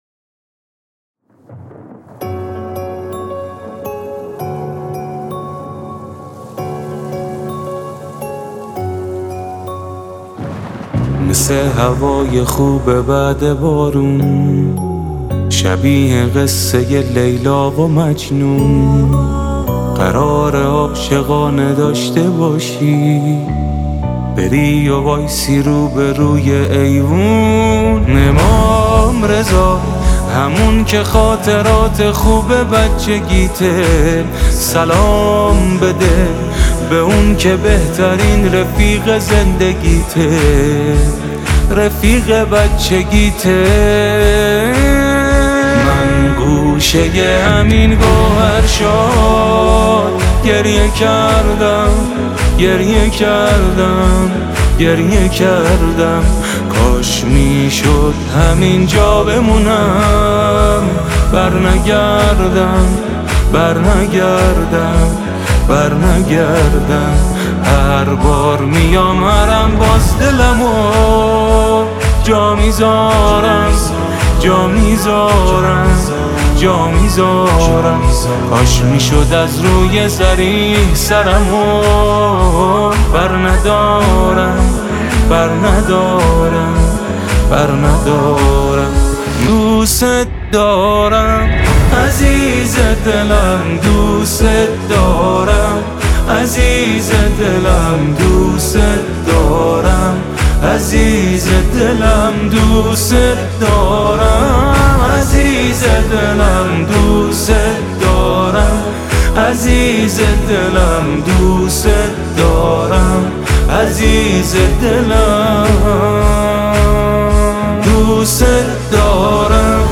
ویژه ولادت امام رضا (علیه‌السلام)